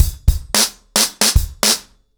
BlackMail-110BPM.13.wav